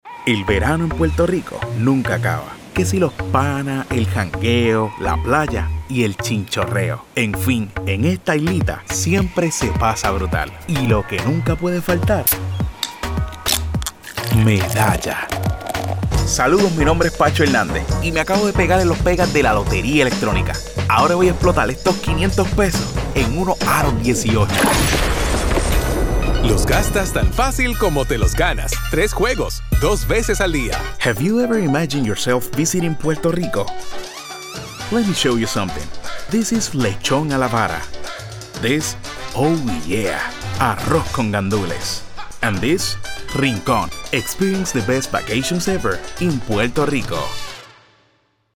A friendly Spanish voice, great for any project!
accented, announcer, caring, concerned, confessional, confident, conversational, foreign-language, Gravitas, high-energy, motivational, professional, spanish-dialect, spanish-showcase, thoughtful, upbeat